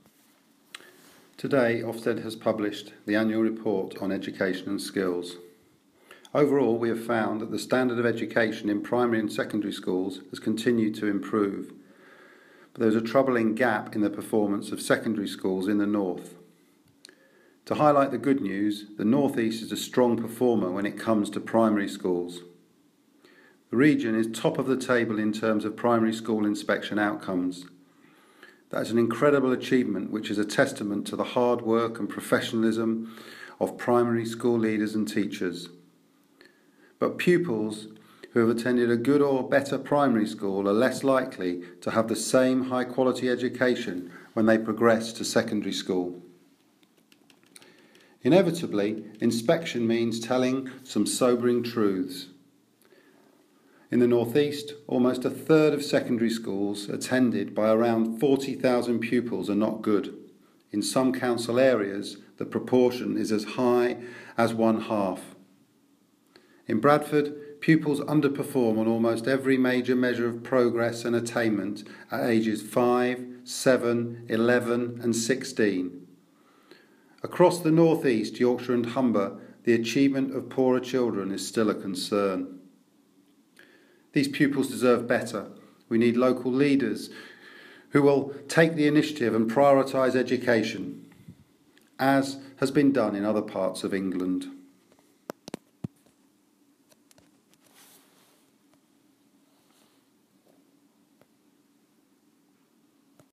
Today (1 December 2015) Ofsted launches its Annual Report 2014/15. Nick Hudson, Ofsted Director for the North, East, Yorkshire and Humber region talks about the standards of education in the area.